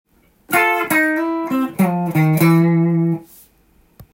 ②のフレーズは和音を使った融合フレーズです。
和音にすると上級者らしい慣れてる感じが出せます。